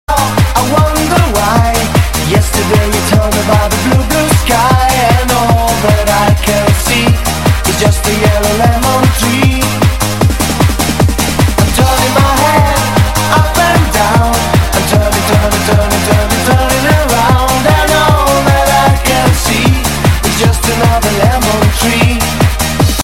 DJ铃声 大小